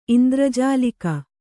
♪ indrajālika